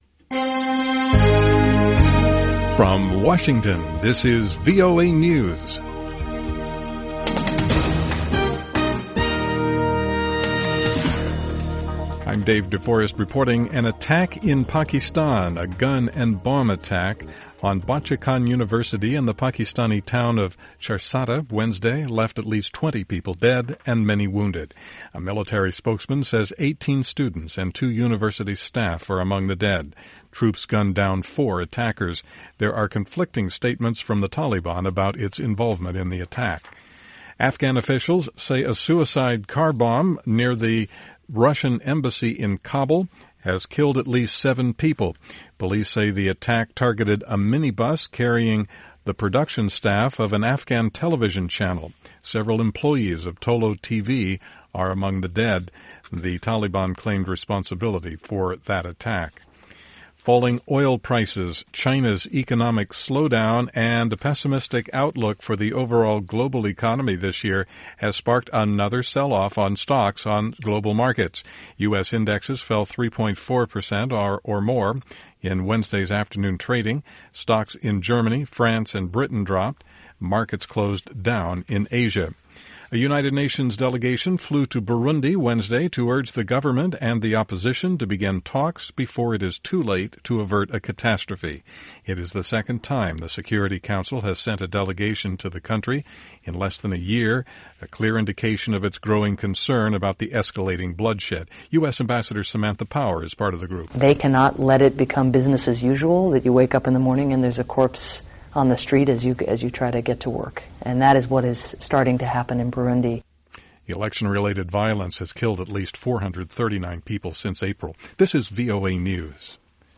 VOA English Newscast: 2000 UTC January 20, 2016